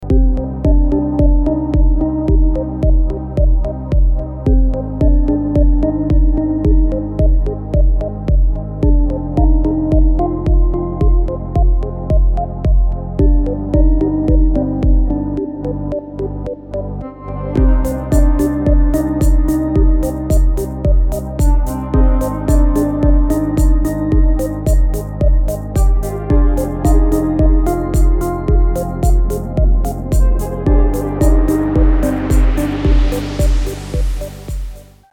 • Качество: 320, Stereo
спокойные
без слов
Downtempo
инструментальные
нежные